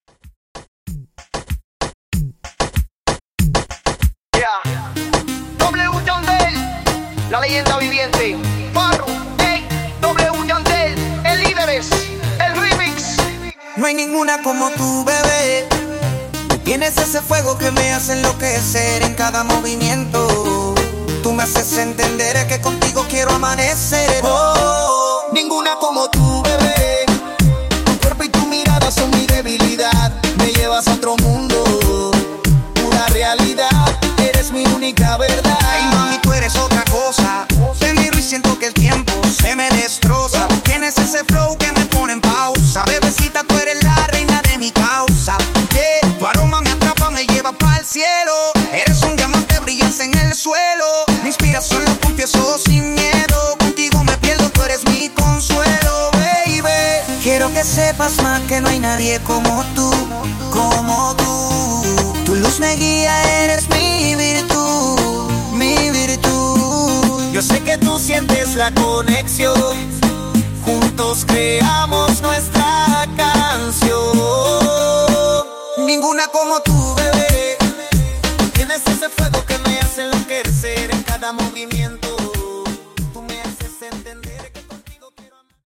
Reggaeton)Date Added